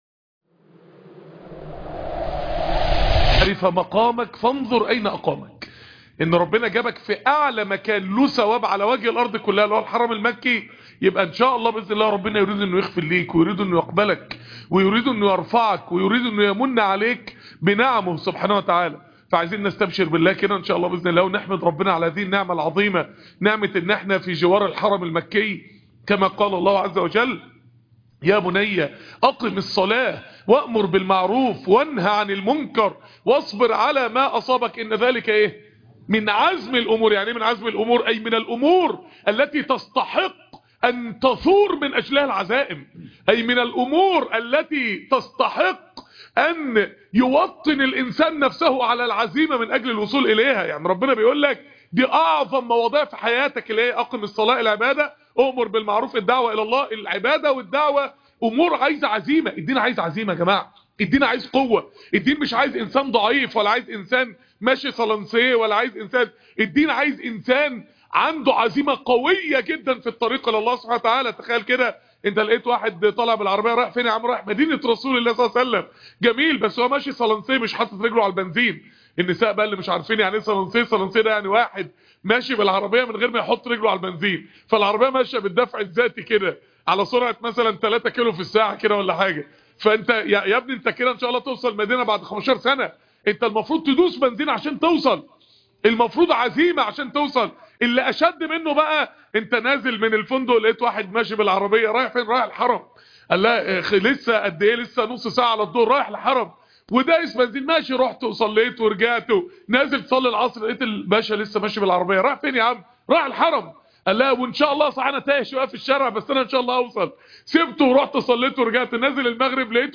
هنا نتعلم العزيمة الدرس - 03 - بمكة عمرة التربية-6